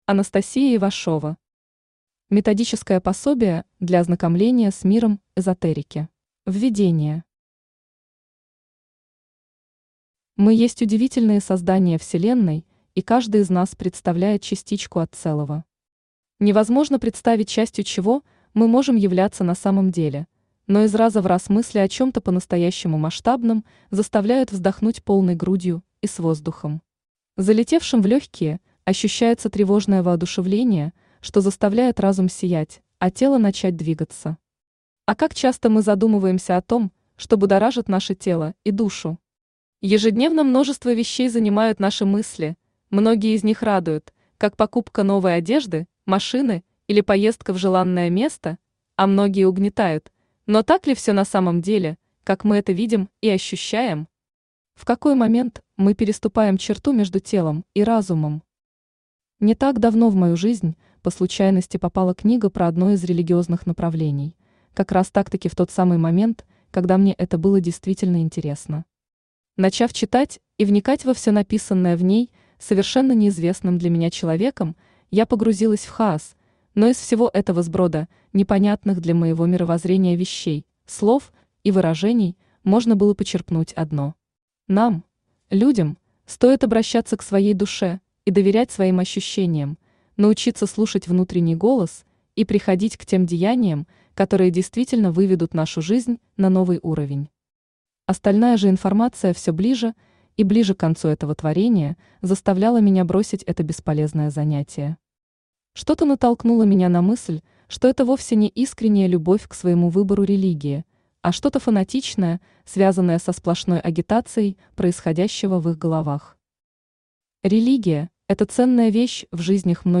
Аудиокнига Методическое пособие для ознакомления с миром эзотерики | Библиотека аудиокниг
Читает аудиокнигу Авточтец ЛитРес.